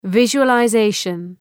Προφορά
{,vıʒʋələ’zeıʃən}